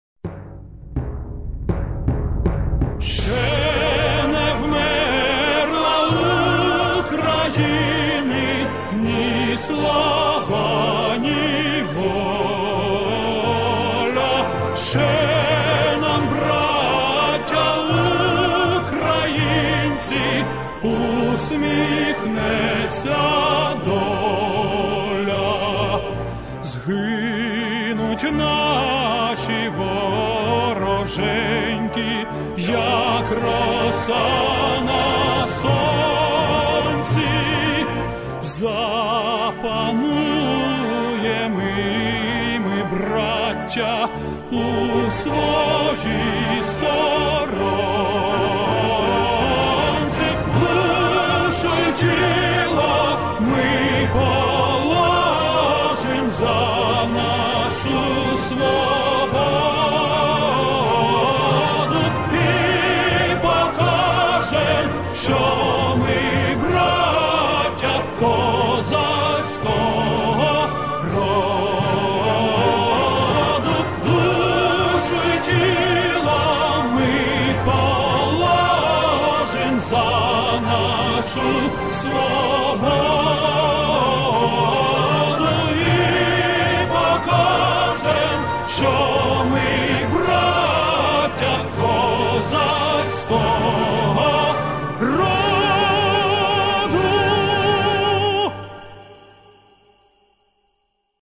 • Качество: высокое
Торжественная мелодия и слова патриотической песни \